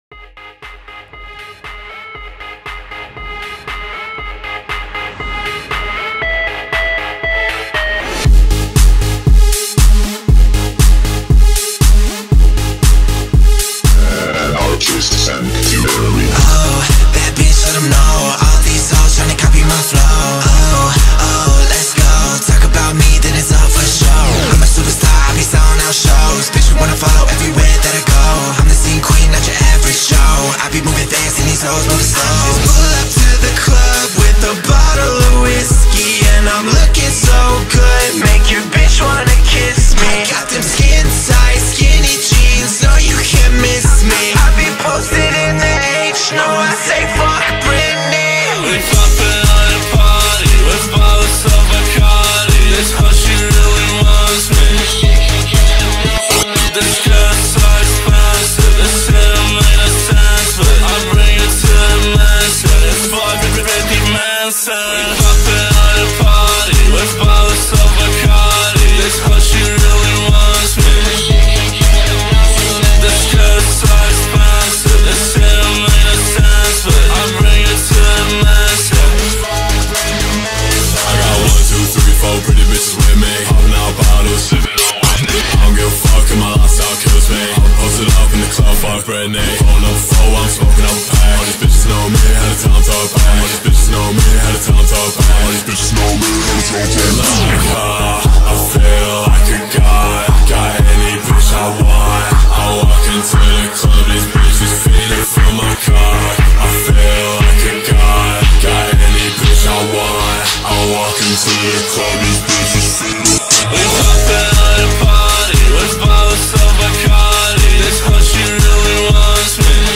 Diss Track